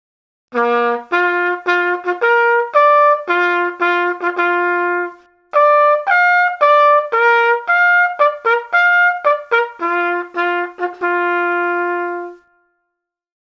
groep6_les1-5-2_blaasinstrumenten2_trompet.mp3